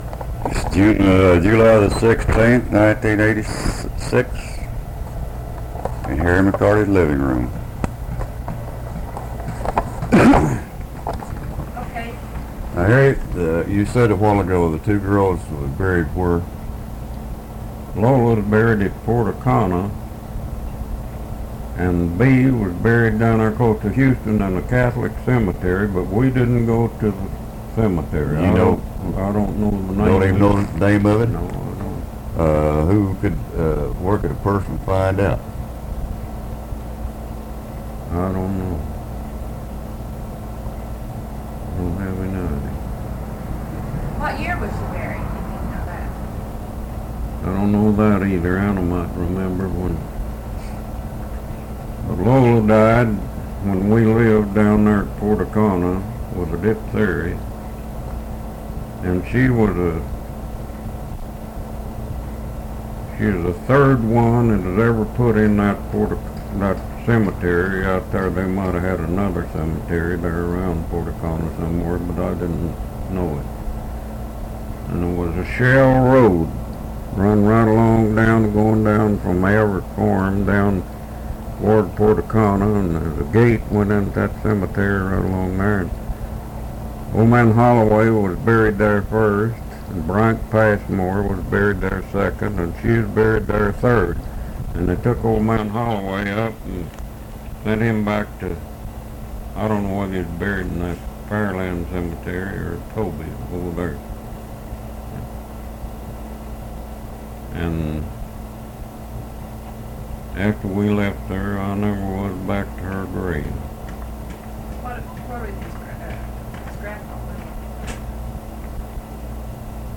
Oral History Archive